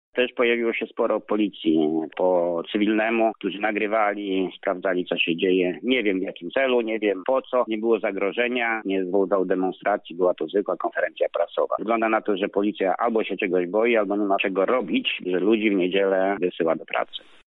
Konferencja z moim udziałem przyciągnęła uwagę wielu mediów – mówi senator Jacek Bury